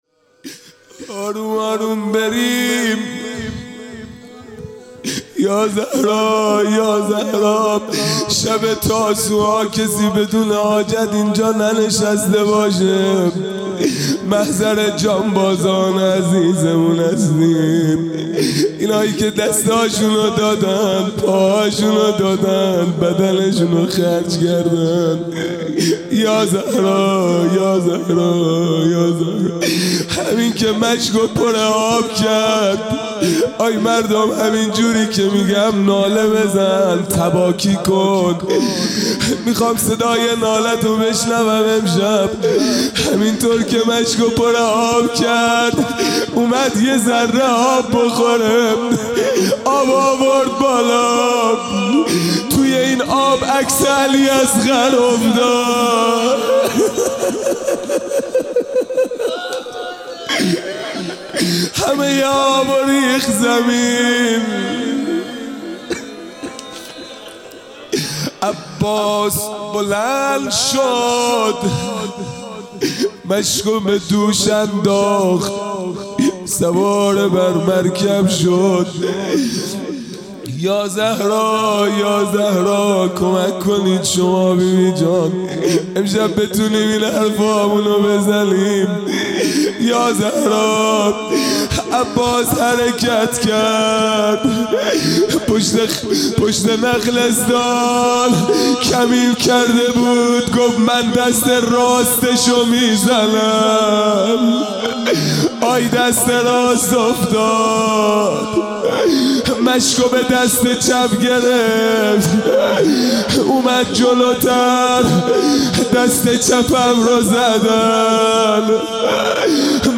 خیمه گاه - هیئت بچه های فاطمه (س) - روضه | کمرم شکست